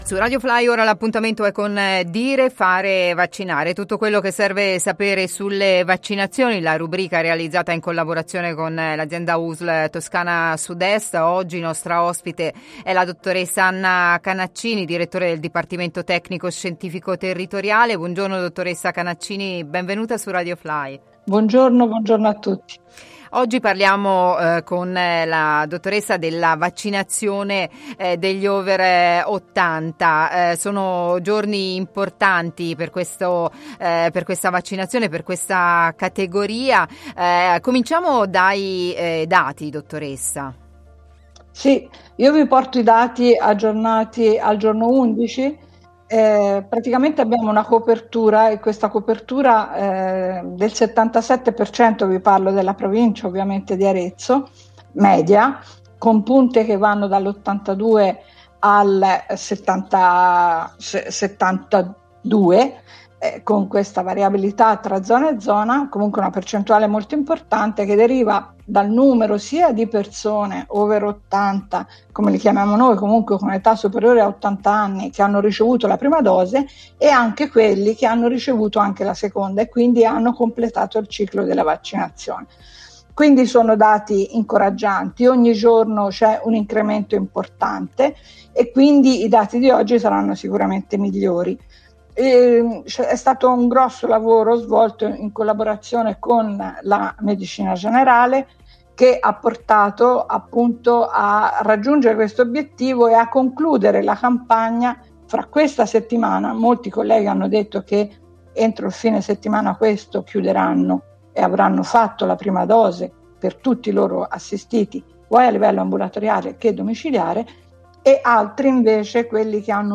intervisterà